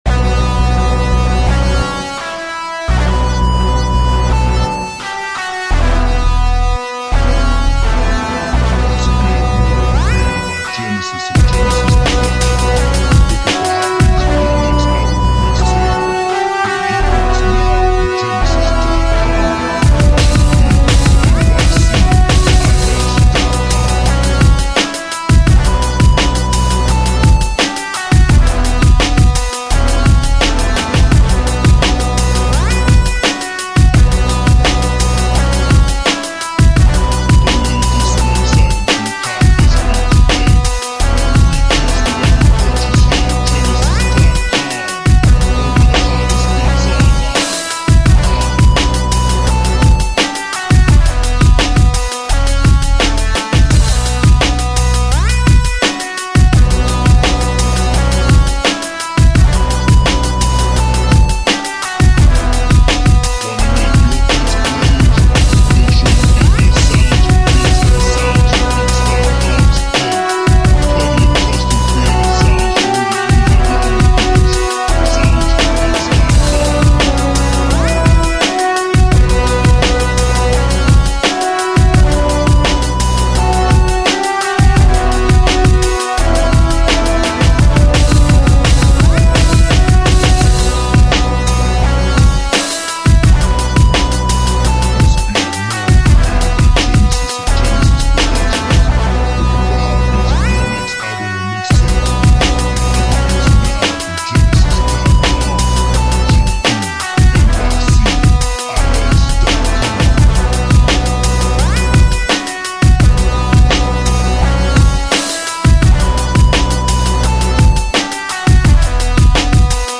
Intense Fighting / Combat Type Rap Beat